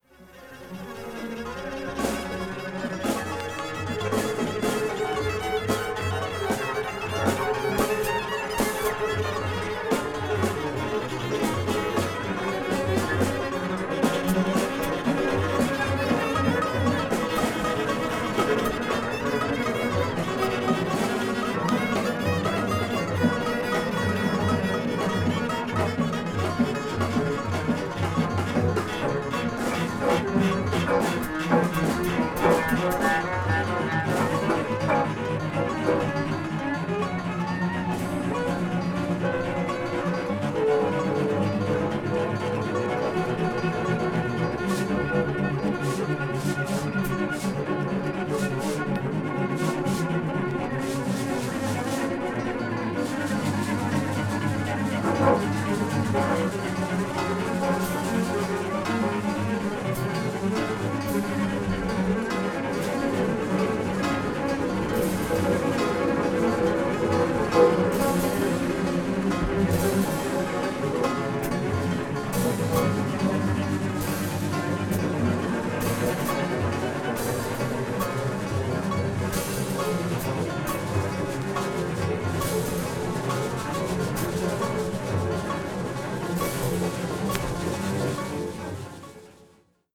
avant-jazz   free improvisation   free jazz